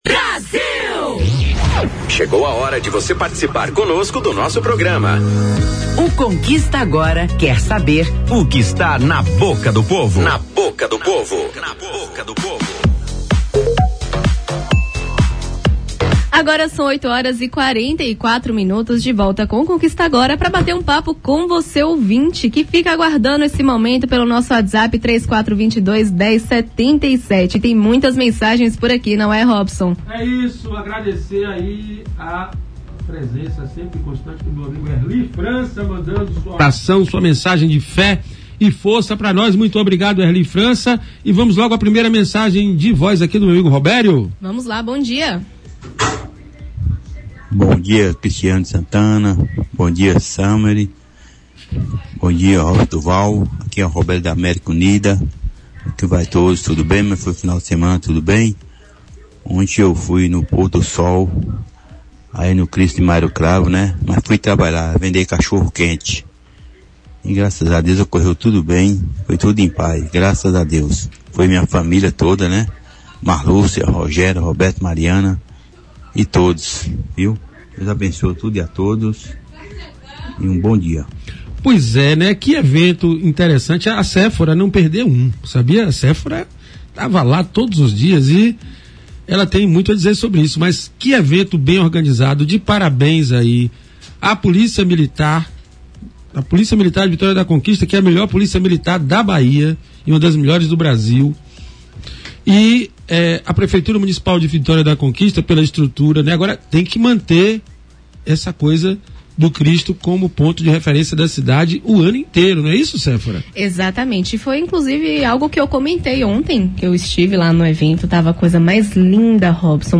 Durante a edição do programa Conquista Agora, na Rádio Brasil, desta segunda-feira (26) diversos ouvintes manifestaram indignação com a rigidez das penalidades e as barreiras tecnológicas enfrentadas para validar o uso das vagas no Centro Comercial.